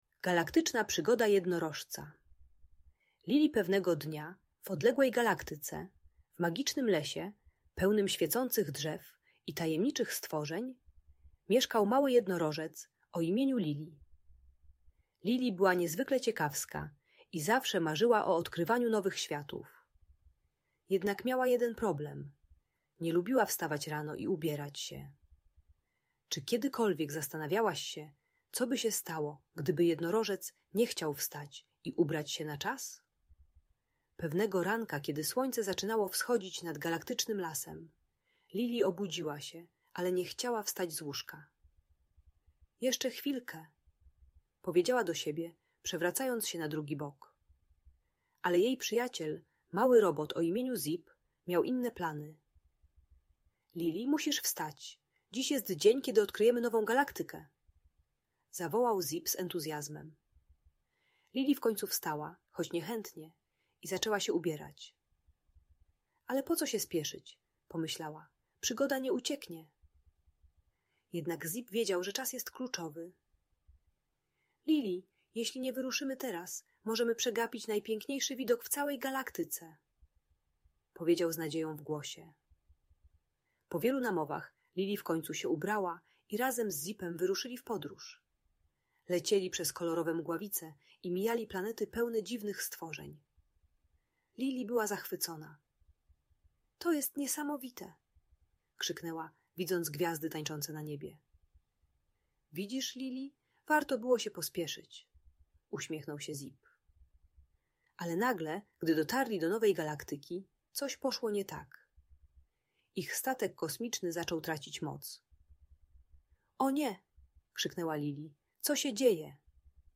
Galaktyczna Przygoda Jednorożca Lili - Audiobajka dla dzieci